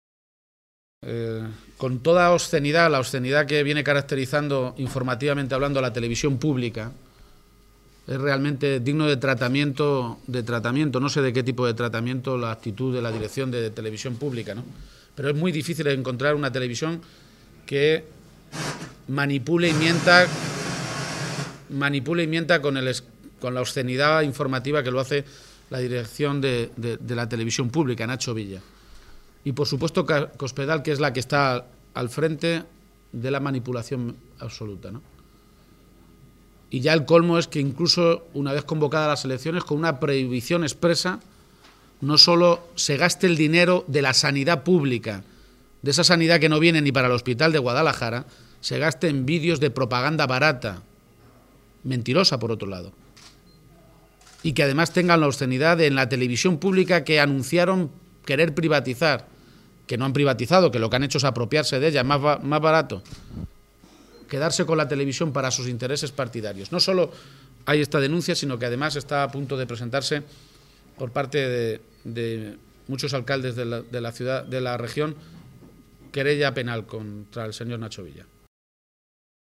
García-Page ha hecho esta afirmación en Guadalajara, donde ha mantenido un encuentro con medios de comunicación para avanzar las principales líneas de sus propuestas para Castilla-La Mancha, mientras Cospedal estaba en una reunión interna del PP convocada para analizar los desastrosos resultados de las elecciones andaluzas, “y convertida en el eje central de todos los problemas, también dentro de su partido”.